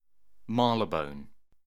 Marylebone station (/ˈmɑːrlɪbən/
En-uk-Marylebone.ogg.mp3